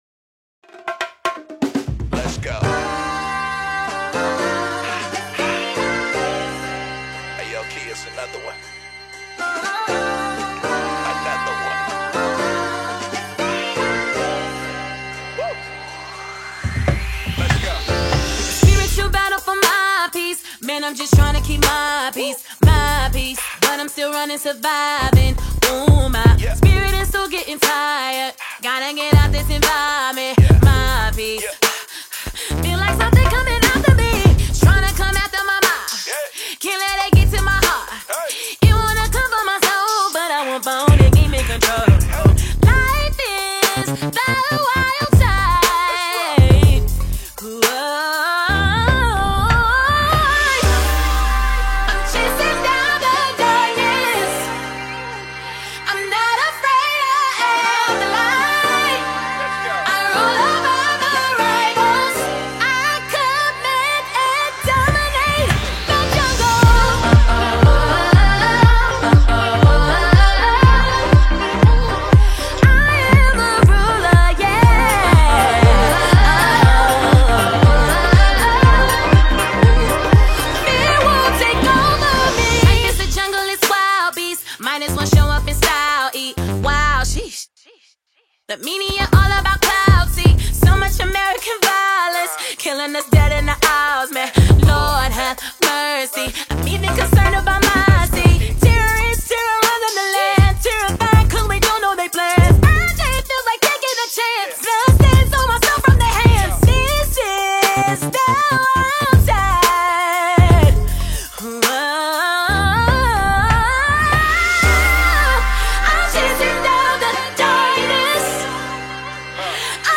The multiple award-winning gospel minister of all time
powerful song